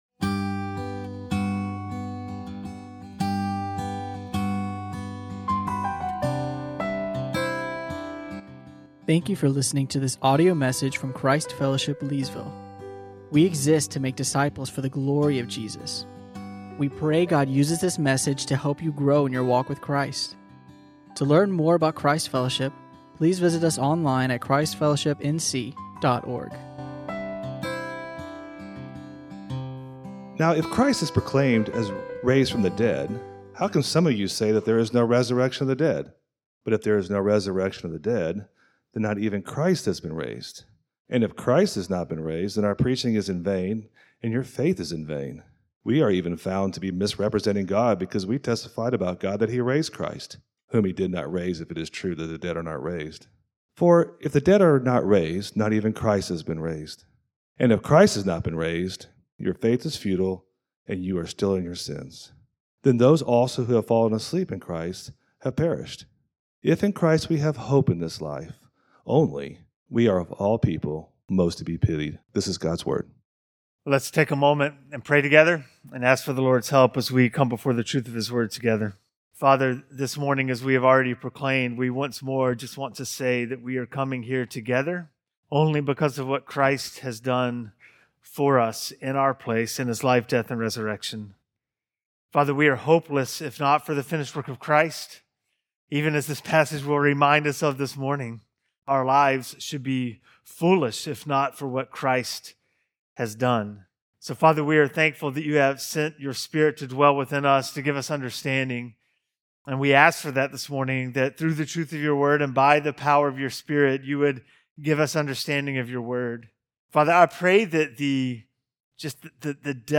teaches on 1 Corinthians 15:12-19.